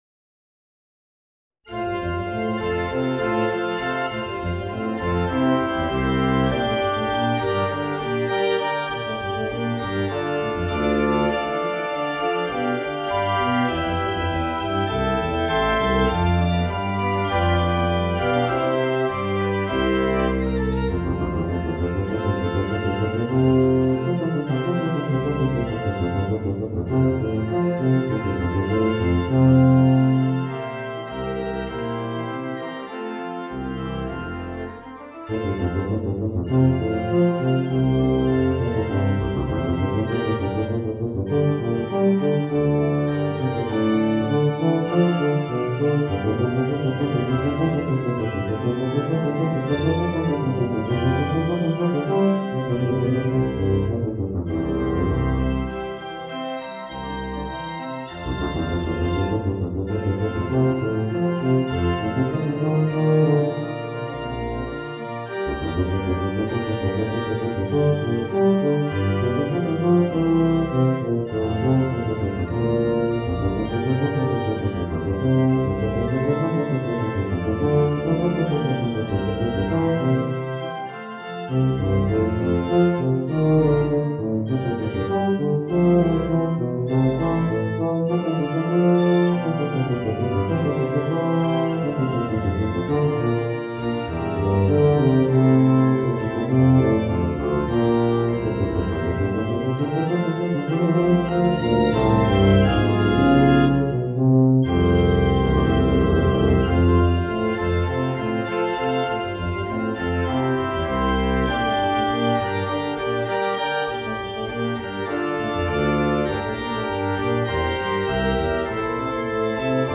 Voicing: Tuba and Organ